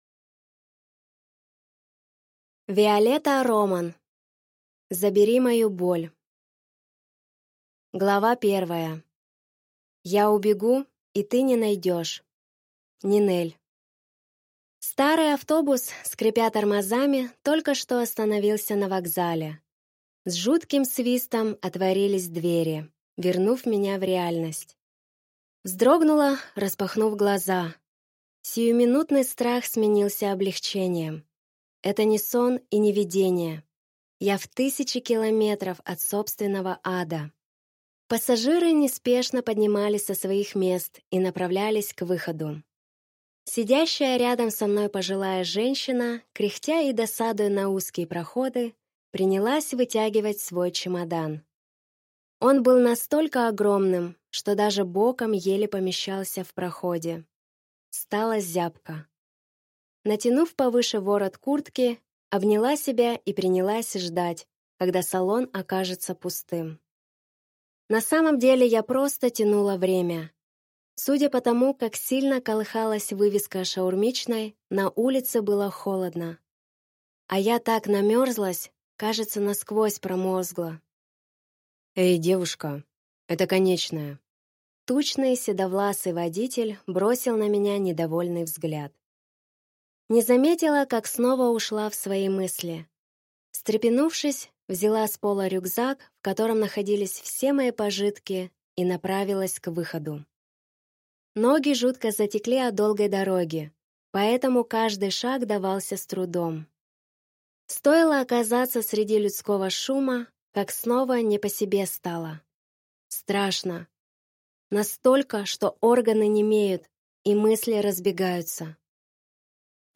Аудиокнига Забери мою боль | Библиотека аудиокниг